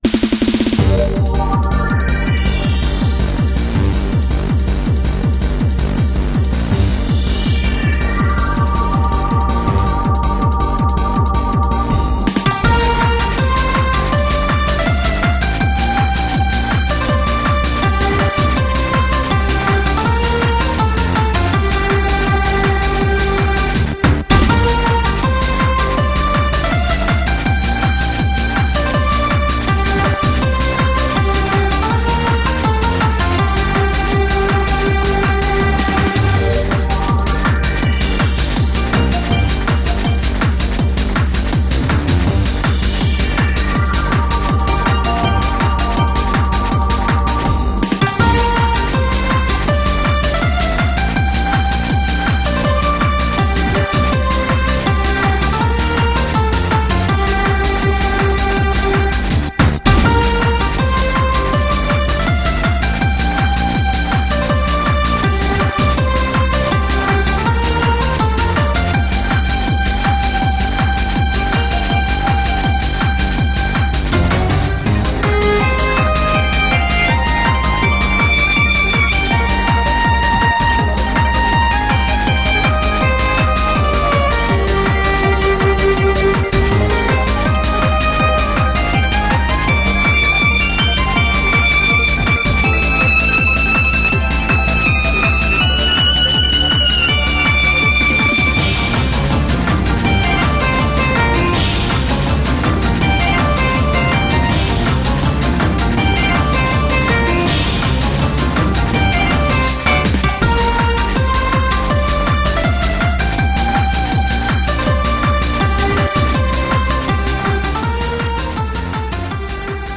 BPM162